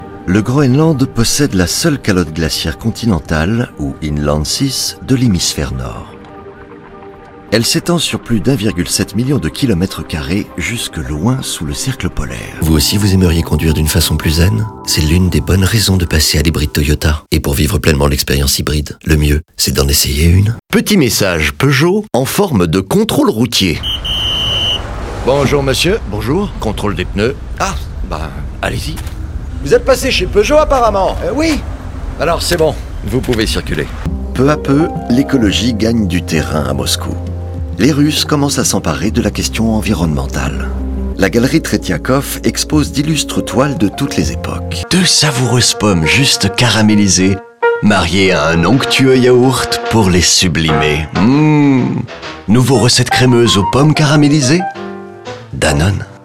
Voix / Doublage
Démo Audio
Publicité, narration/voice-over.